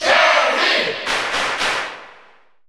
Category: Crowd cheers (SSBU) You cannot overwrite this file.
Terry_Cheer_German_SSBU.ogg